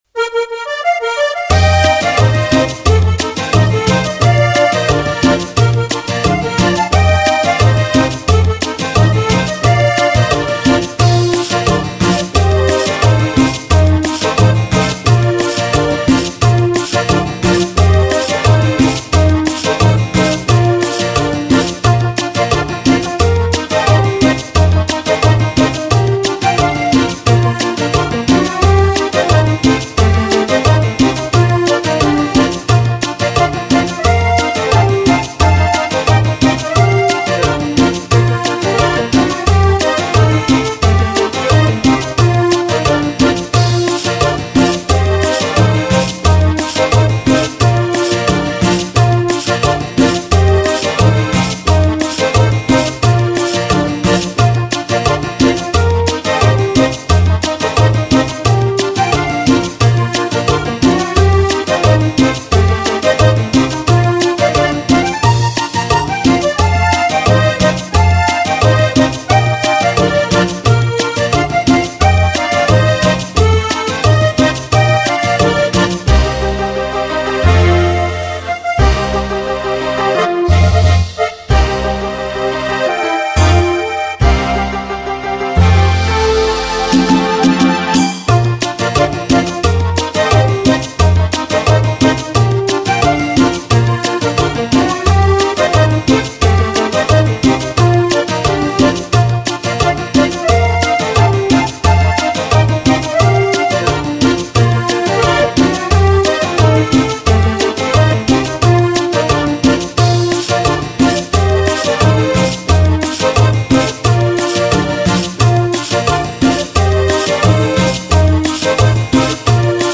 Versió instrumental